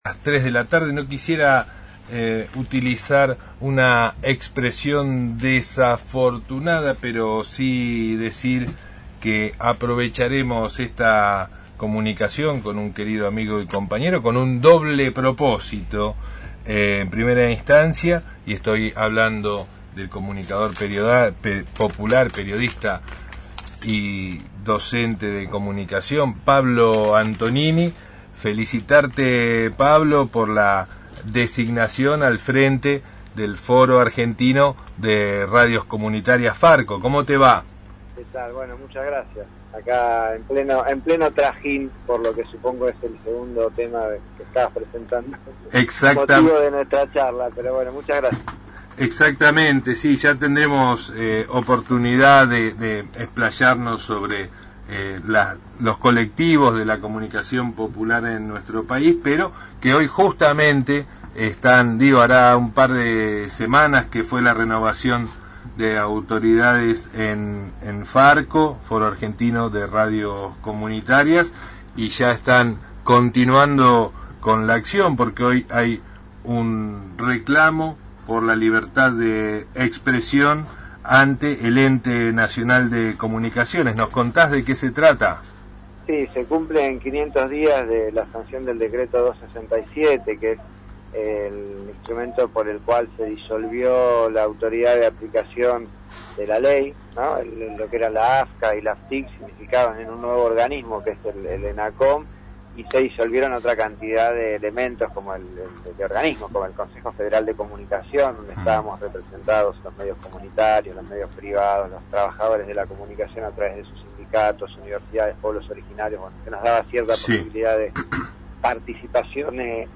Conducción